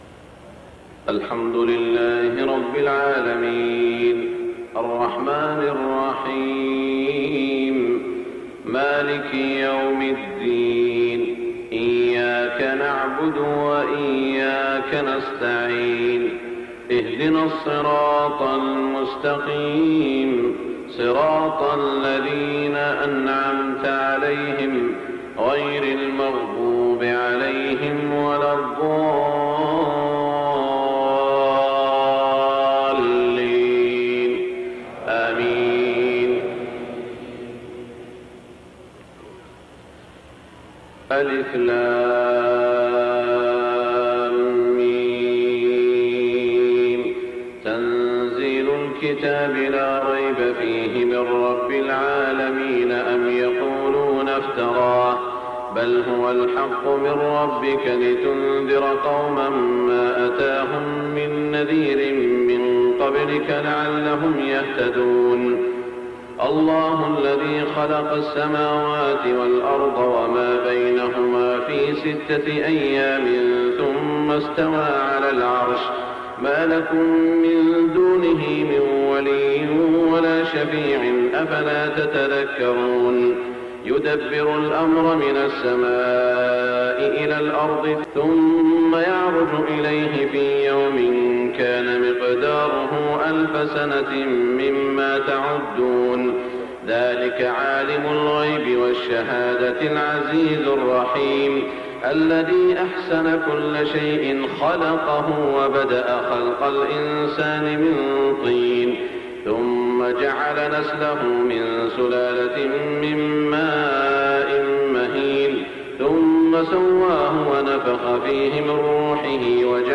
صلاة الفجر 1424 سورتي السجدة والانسان > 1424 🕋 > الفروض - تلاوات الحرمين